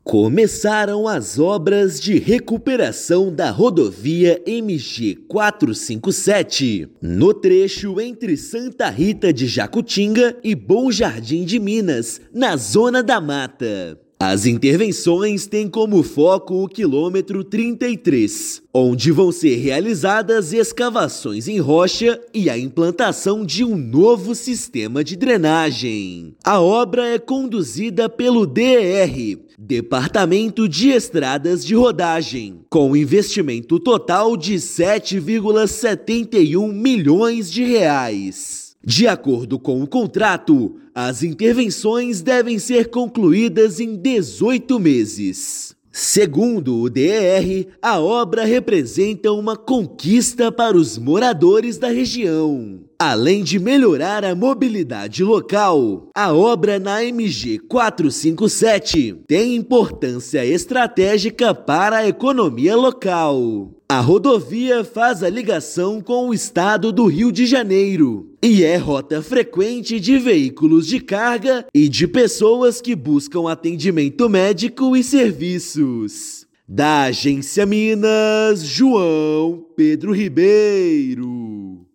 Investimento de R$ 7,71 milhões vai melhorar segurança e tráfego na rodovia, que liga Minas ao Rio de Janeiro e é rota importante para veículos de carga. Ouça matéria de rádio.